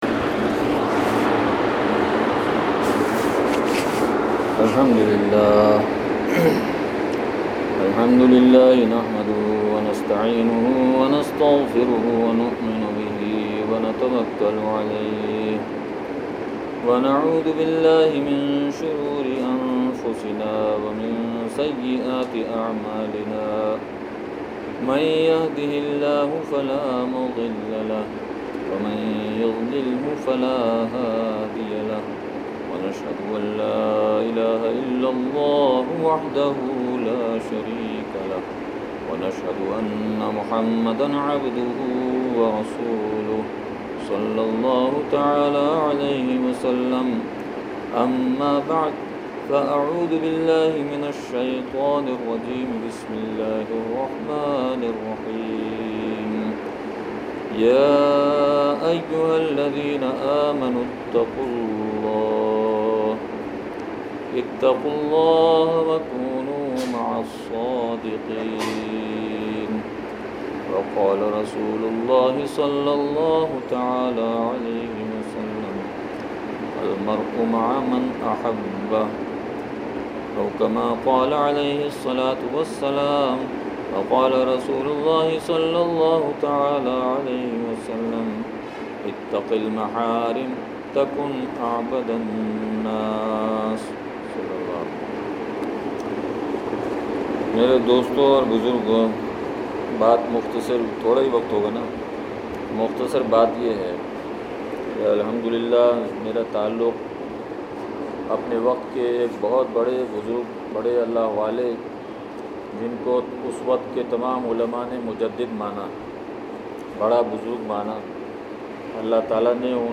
بیان نمبر۲ – مینگورہ سوات